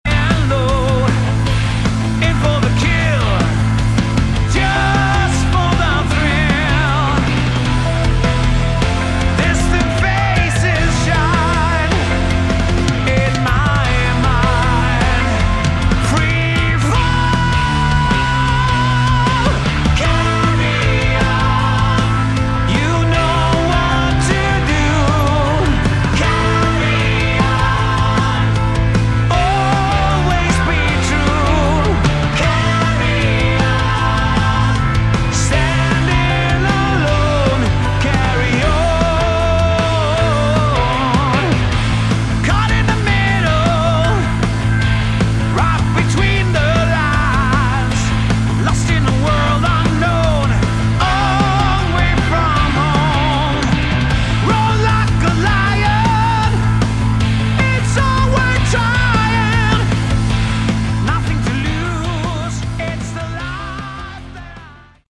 Category: Hard Rock
guitar
vocals
bass
organ, keyboards
drums